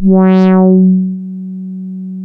MG MOD.F#3 1.wav